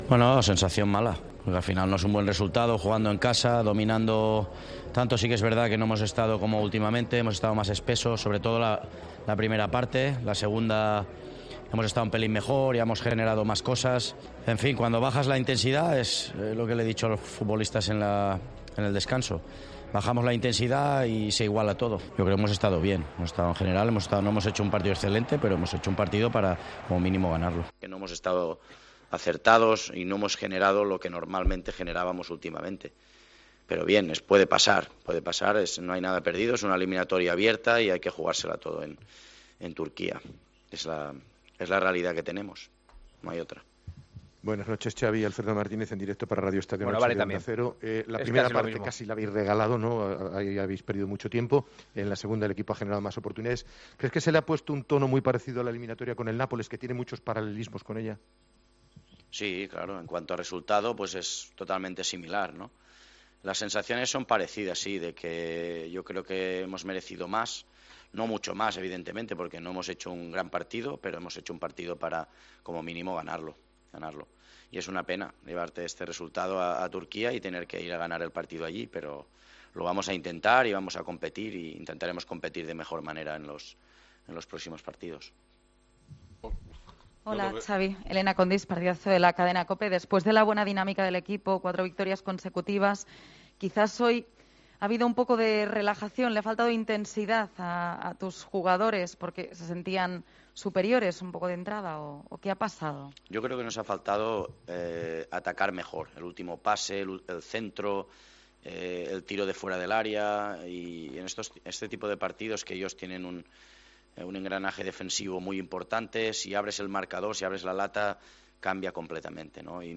En rueda de prensa